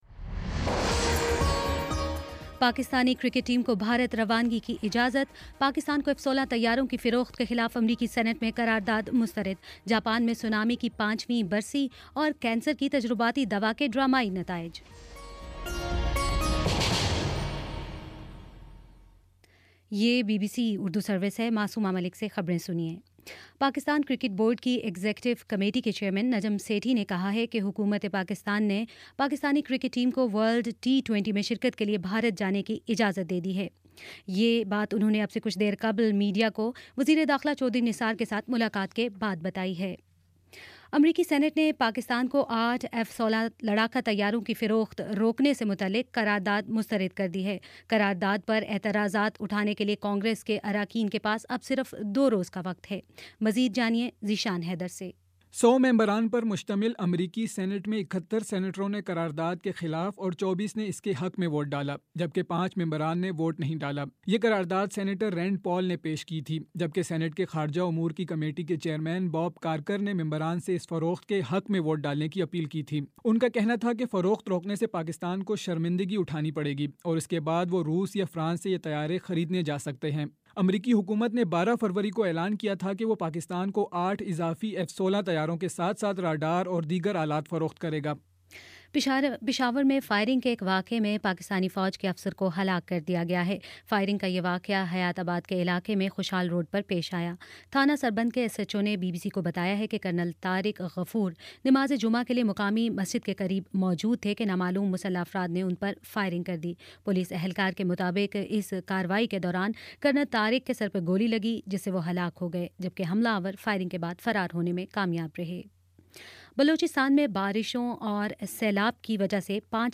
مارچ 11 : شام پانچ بجے کا نیوز بُلیٹن